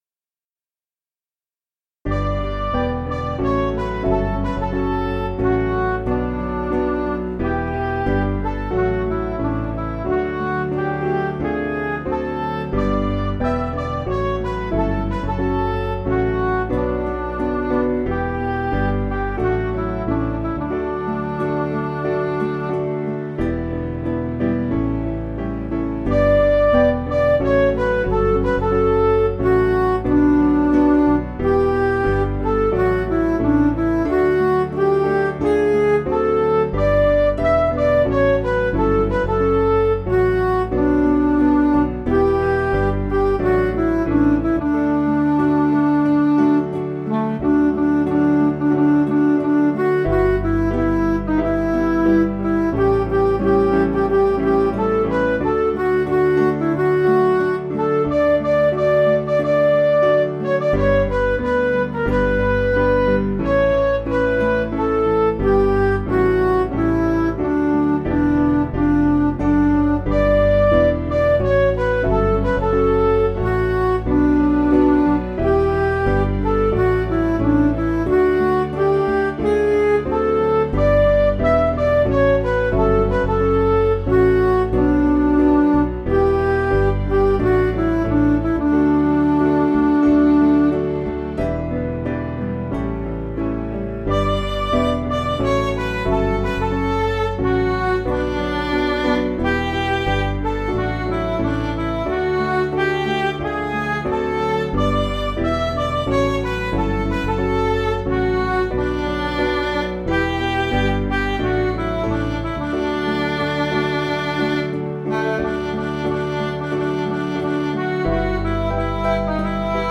Catholic hymn
Piano & Instrumental
Midi